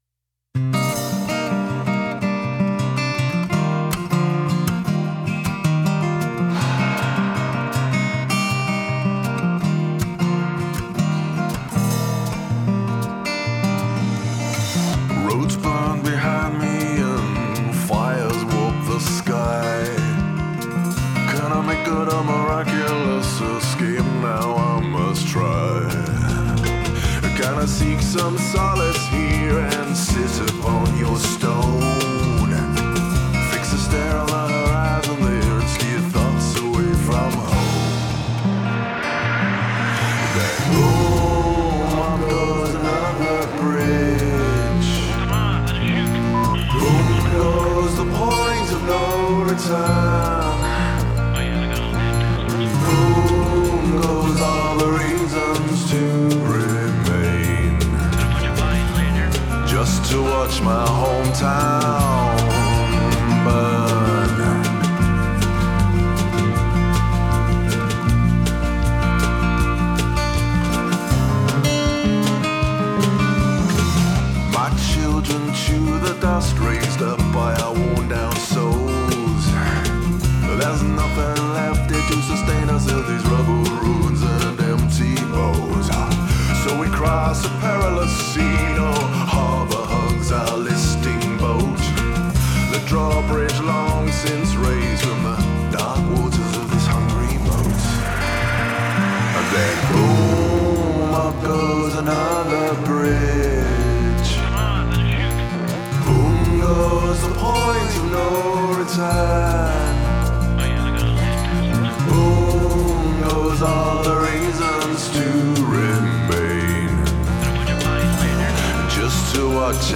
Acoustic Guitars,Electronica,
SFX & Voices
Cajon & Assorted Percussion